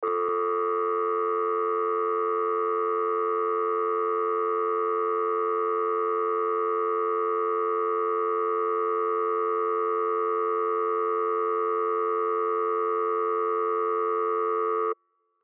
دانلود آهنگ تلفن 2 از افکت صوتی اشیاء
جلوه های صوتی
دانلود صدای تلفن 2 از ساعد نیوز با لینک مستقیم و کیفیت بالا